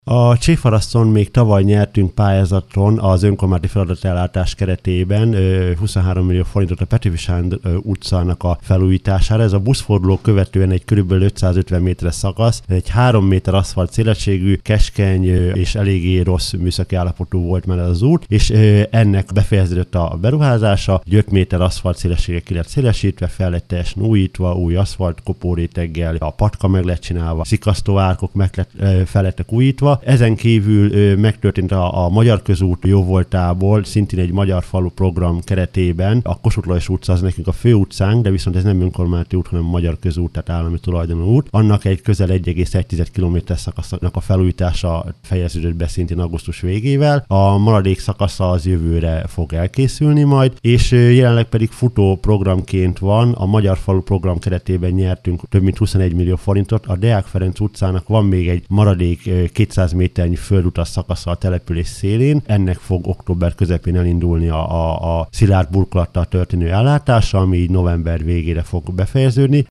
A térségben számos útfelújítás zajlik, legutóbb Csévharaszton adtak át felújított útszakaszt. Pulisch József polgármestert hallják: